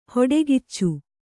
♪ hoḍegiccu